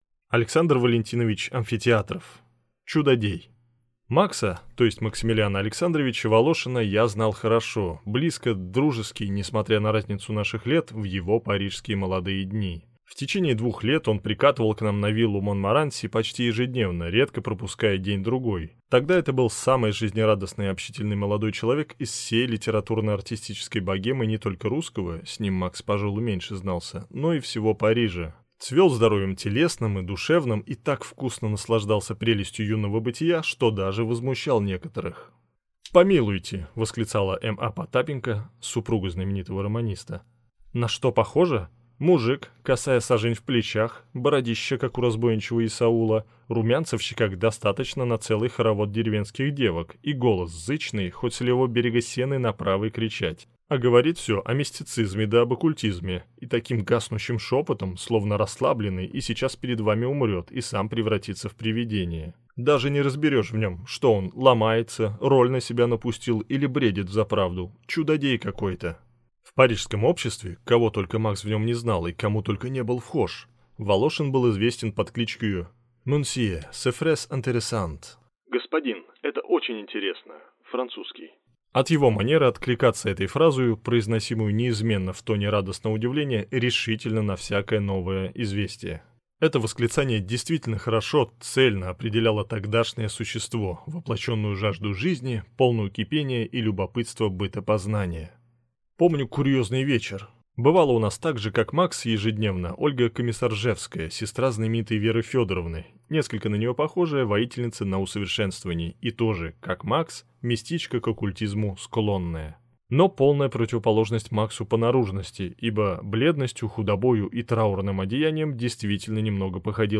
Аудиокнига Чудодей | Библиотека аудиокниг